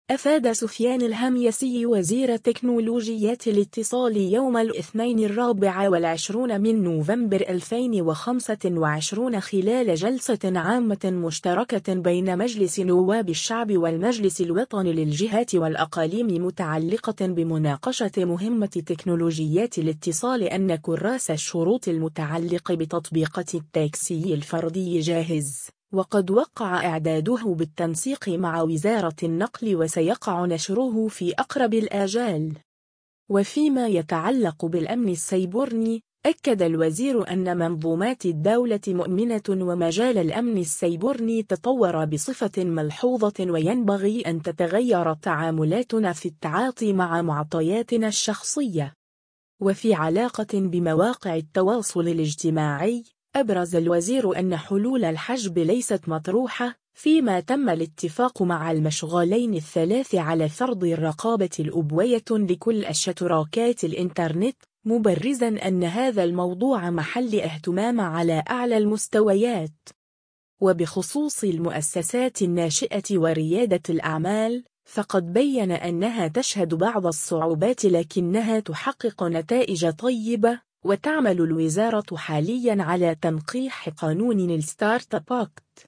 أفاد سفيان الهميسي وزير تكنولوجيات الإتصال يوم الإثنين 24 نوفمبر 2025 خلال جلسة عامة مشتركة بين مجلس نواب الشعب والمجلس الوطني للجهات والأقاليم متعلقة بمناقشة مهمة تكنولوجيات الإتصال أن كراس الشروط المتعلق بتطبيقات التاكسي الفردي جاهز، وقد وقع إعداده بالتنسيق مع وزارة النقل وسيقع نشره في أقرب الآجال.